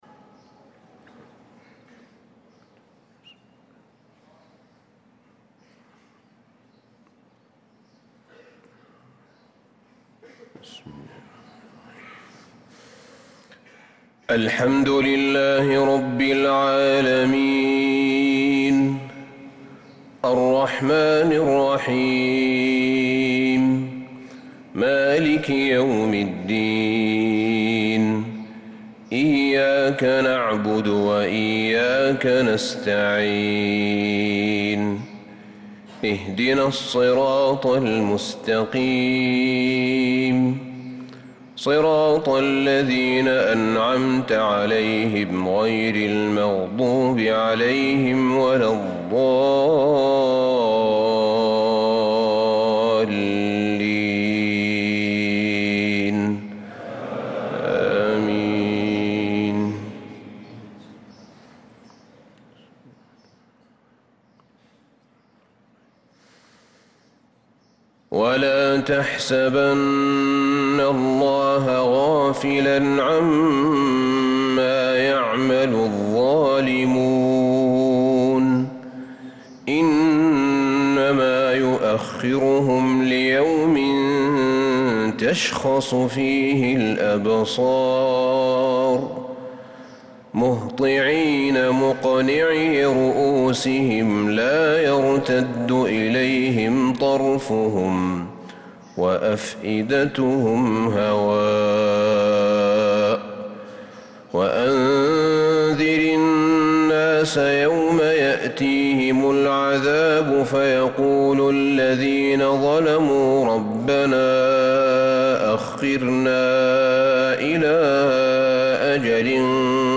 صلاة الفجر للقارئ أحمد بن طالب حميد 18 ربيع الأول 1446 هـ